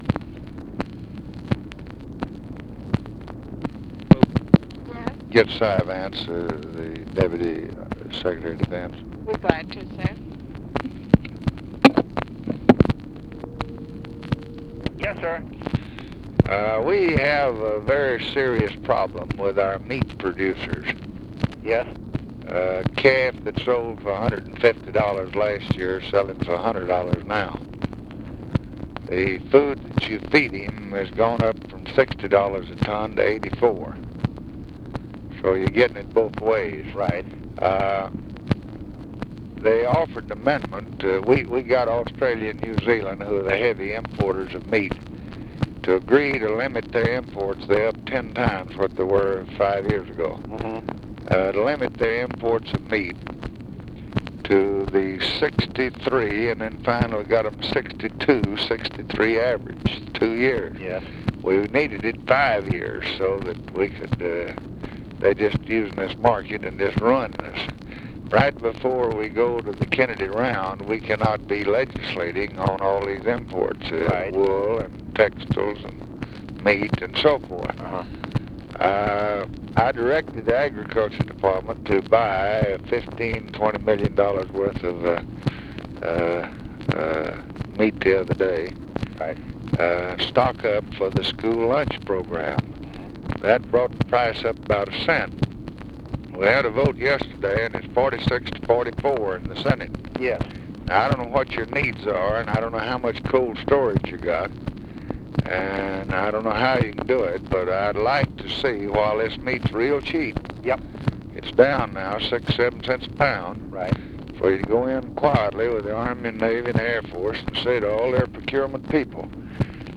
Conversation with CYRUS VANCE, March 7, 1964
Secret White House Tapes